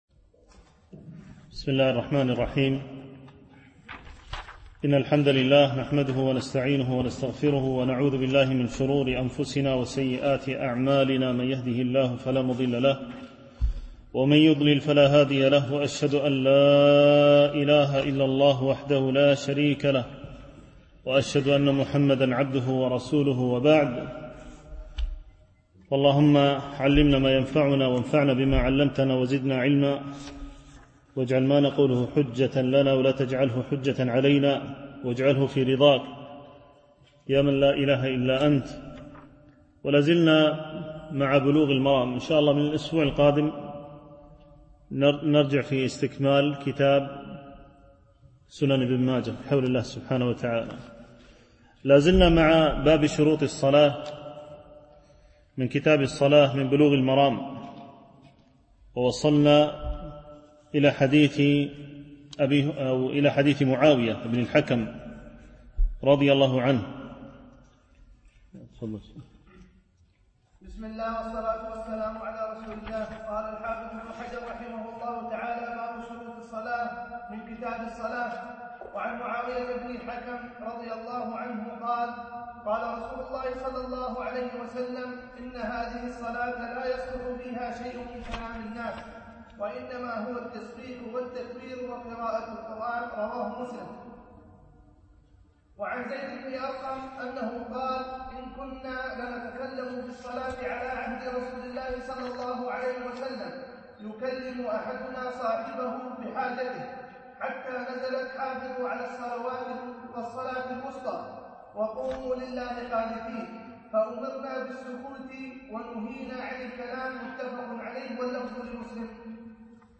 دروس مسجد عائشة (برعاية مركز رياض الصالحين ـ بدبي)
التنسيق: MP3 Mono 22kHz 32Kbps (VBR)